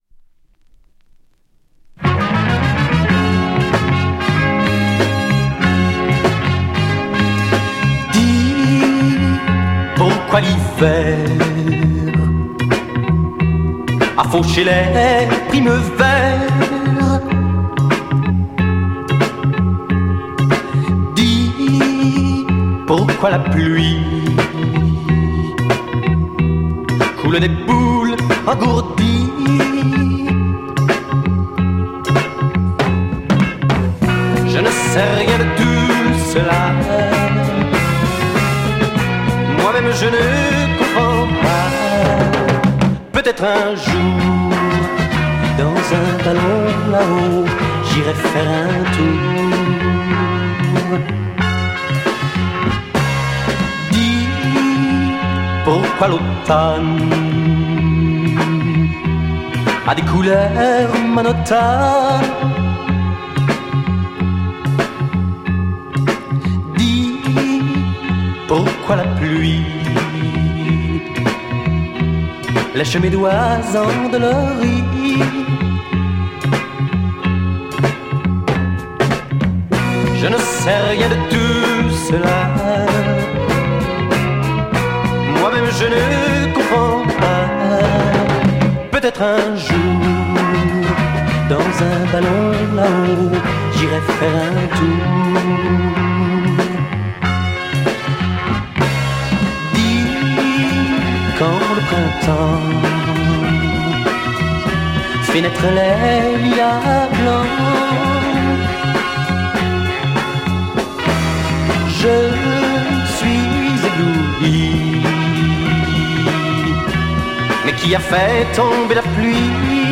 Brilliant French Folk Psych groover single